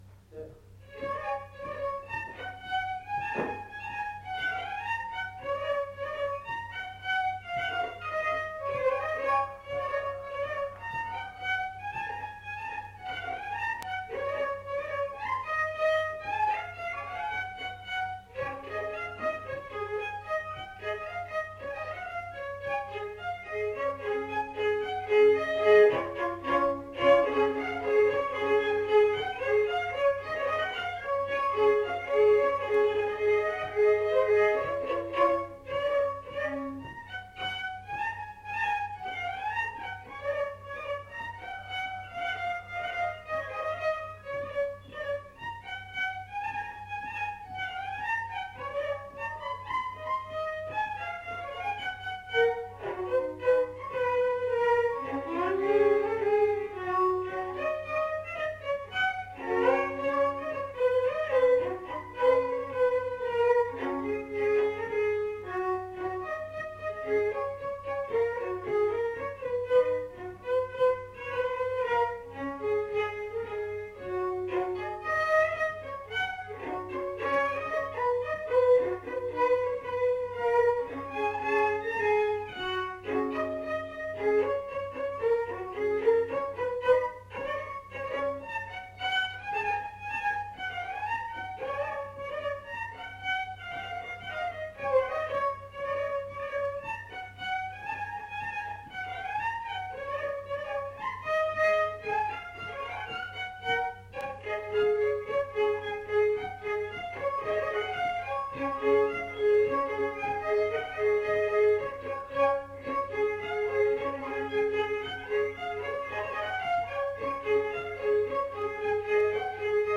Scottish
Aire culturelle : Lomagne
Département : Gers
Genre : morceau instrumental
Instrument de musique : violon
Danse : scottish